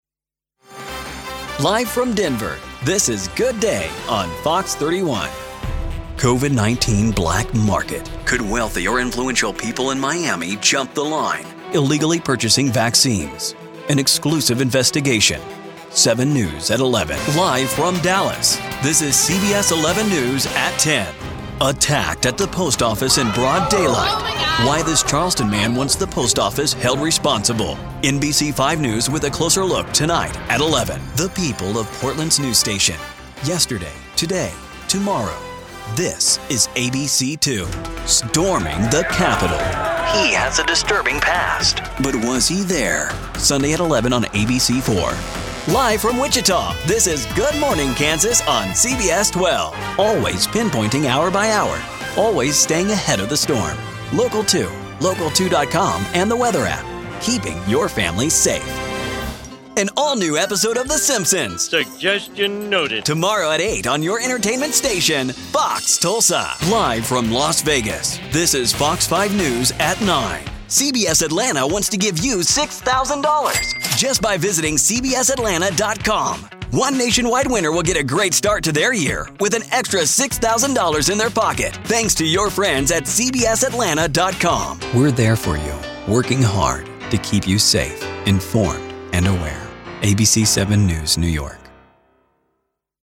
Promo - Network Affiliate
Teen
Young Adult
Middle Aged